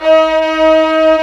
STR VIOLA02L.wav